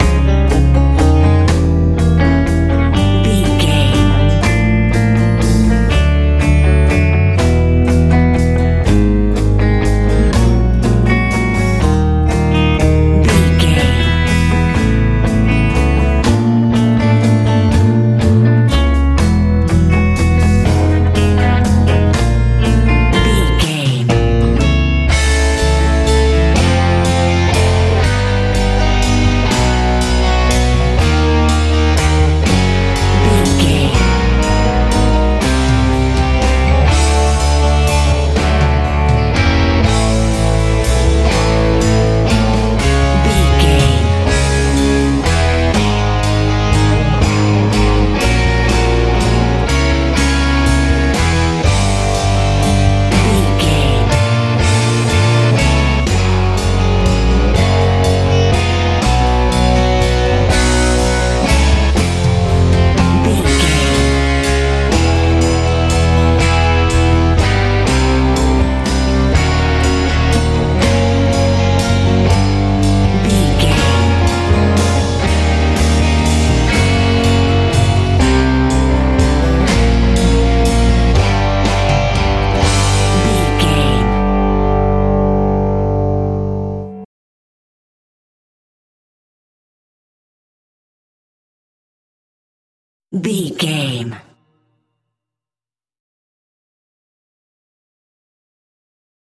modern radio rock feel
Ionian/Major
E♭
groovy
funky
electric guitar
bass guitar
drums